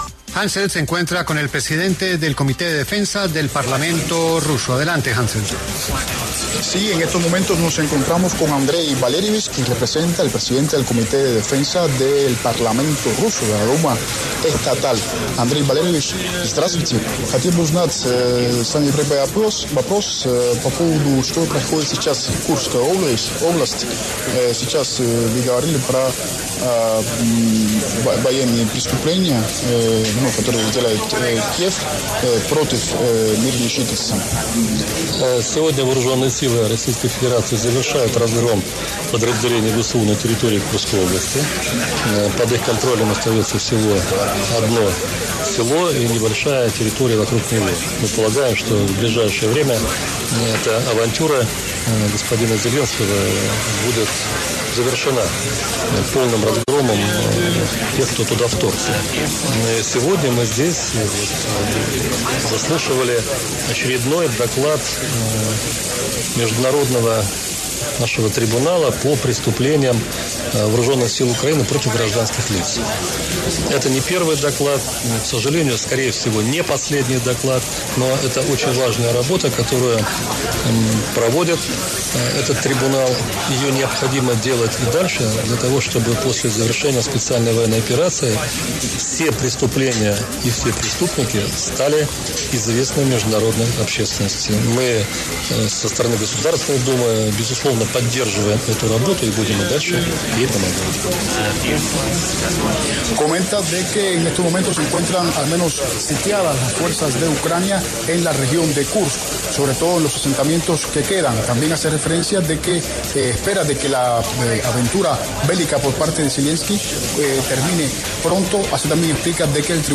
Andréi Kartapolov, presidente del Comité de Defensa Ruso, conversó con La W sobre la situación de la guerra con Ucrania, las acciones de Zelenski y los diálogos con EE.UU.